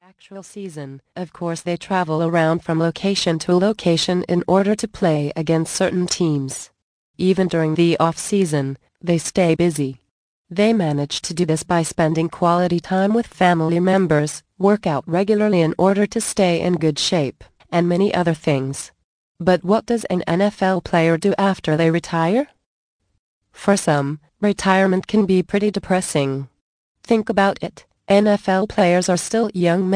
Easy Retirement Planning Tips Audio Book. Vol. 6 of 8